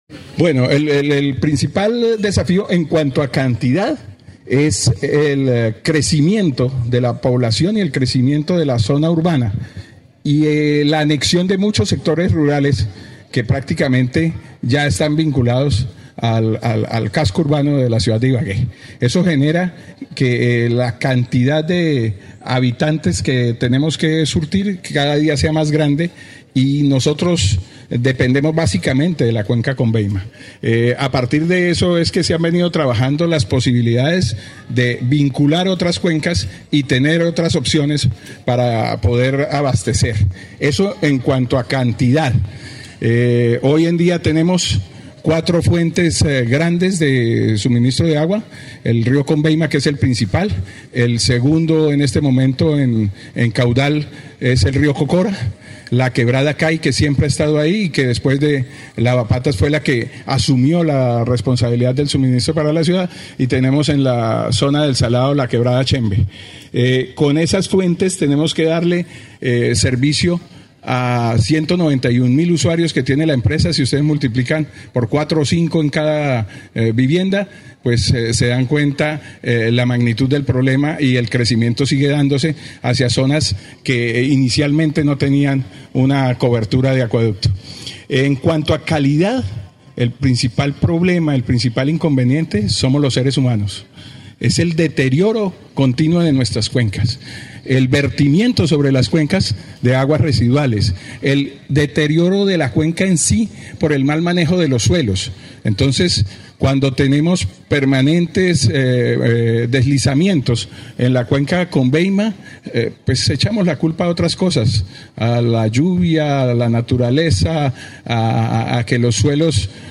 Por eso, proteger las cuencas es fundamental”, señaló, durante su participación en el conversatorio El Agua Nos Une Como Territorio.